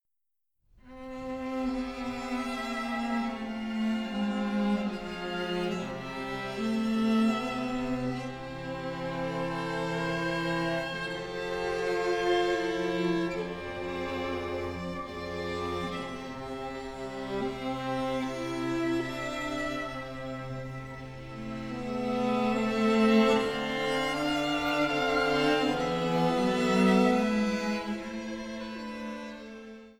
Chor, Klavier